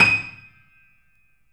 Index of /90_sSampleCDs/E-MU Producer Series Vol. 5 – 3-D Audio Collection/3D Pianos/BoesPlayHardVF04